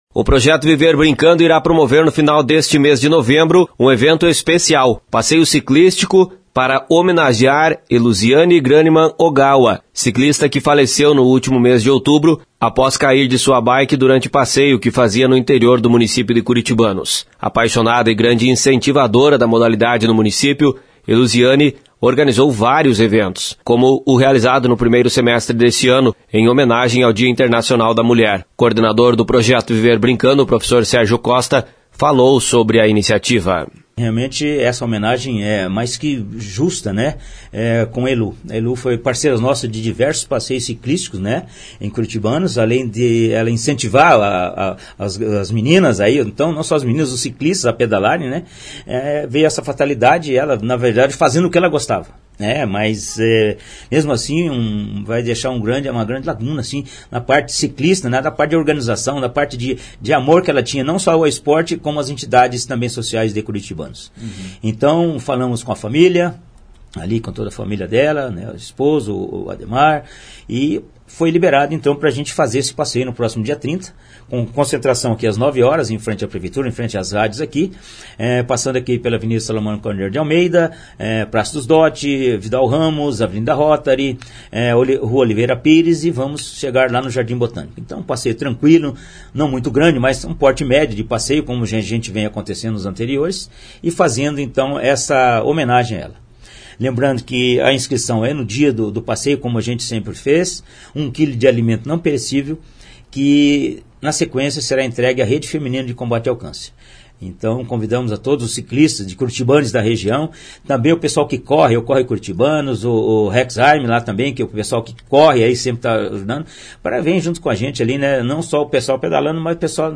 Informações com o repórter